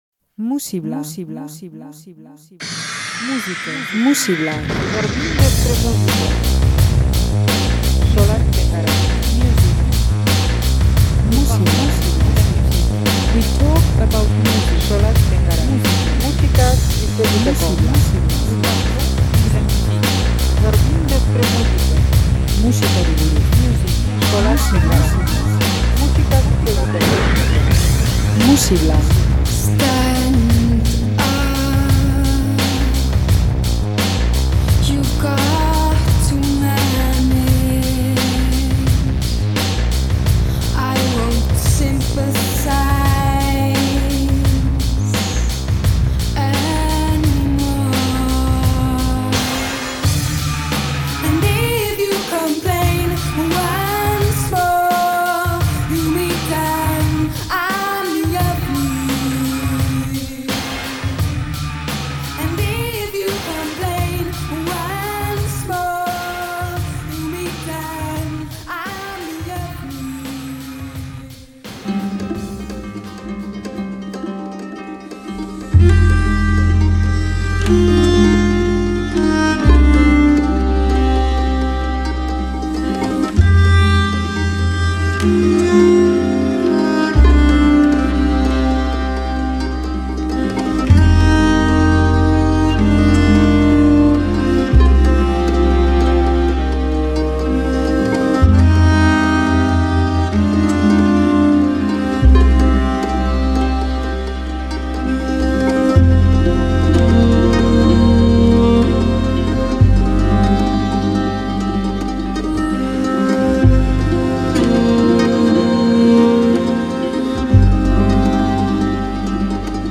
Sonoritate erakargarriak eta instrumentazio naif eta bitxia.